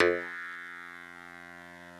genesis_bass_029.wav